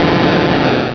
Cri de Léviator dans Pokémon Rubis et Saphir.
Cri_0130_RS.ogg